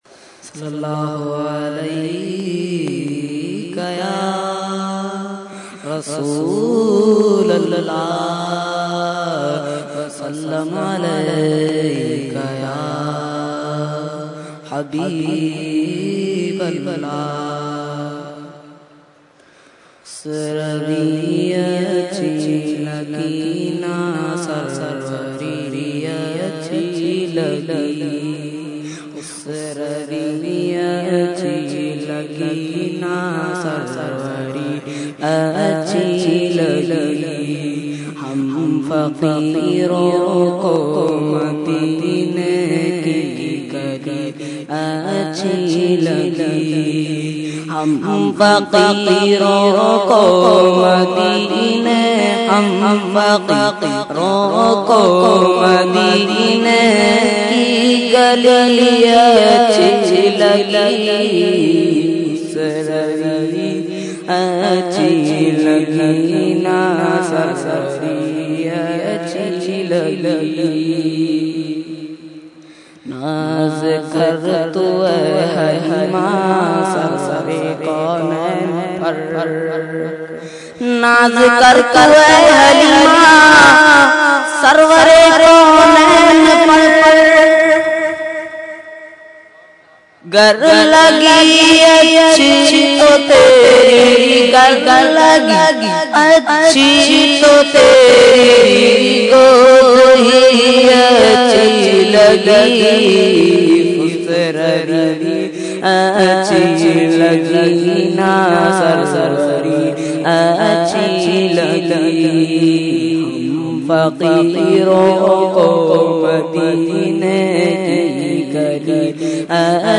Category : Naat | Language : UrduEvent : Urs e Makhdoom e Samnani 2013